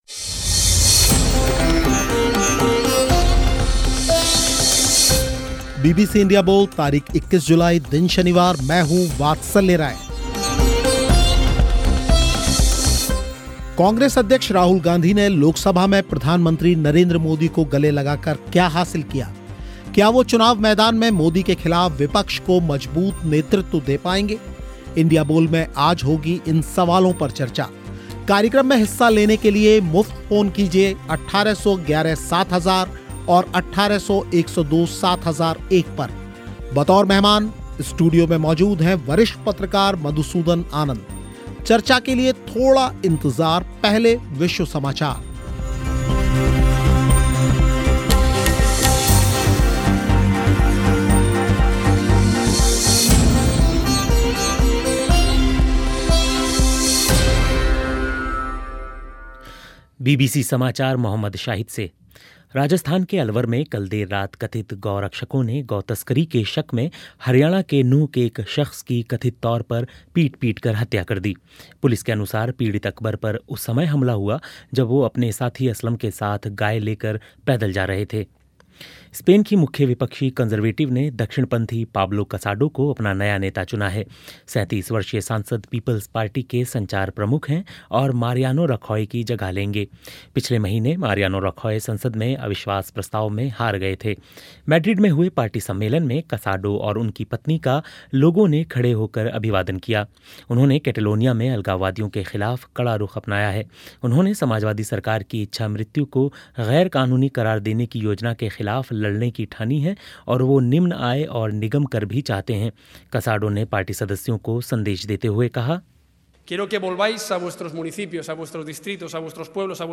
और श्रोताओं ने भी रखी अपनी राय